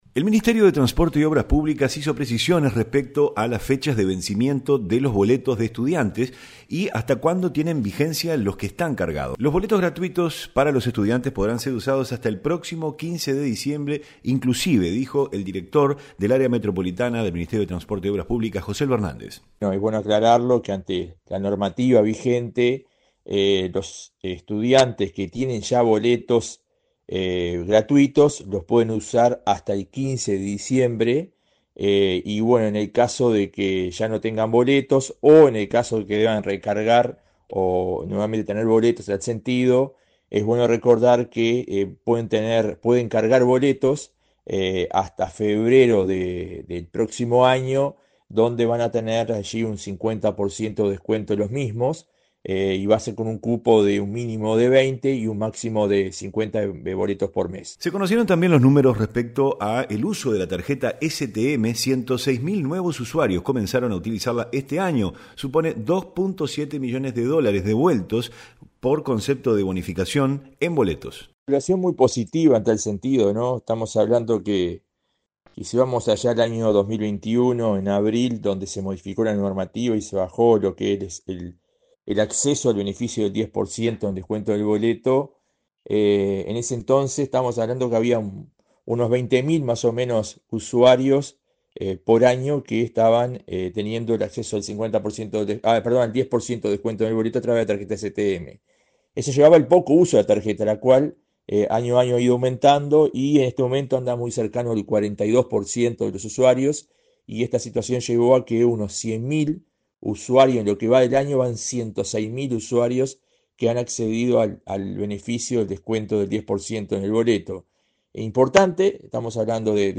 REPORTE-BOLETOS.mp3